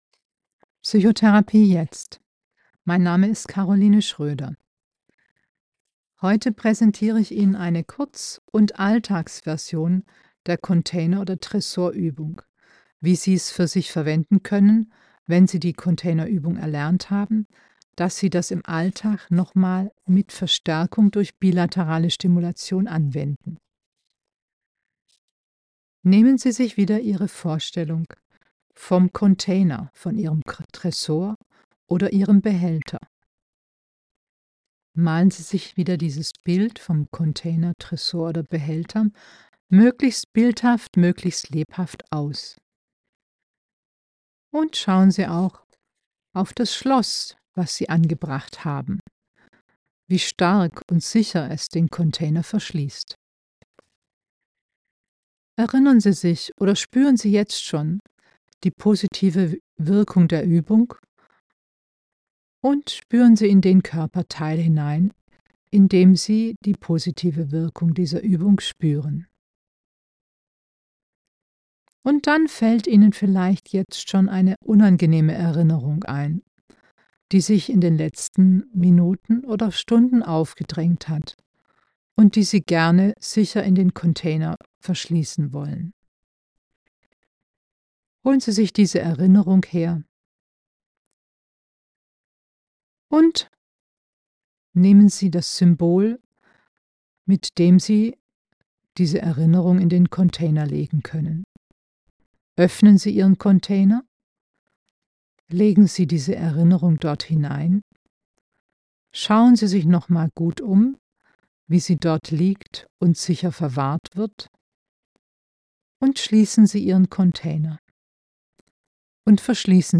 Stabilisierungsübung mit BLS aus der EMDR Eye Movement Desensitization and Reprocessing Therapie Alltagsversion um Erinnerungen abzulegen ...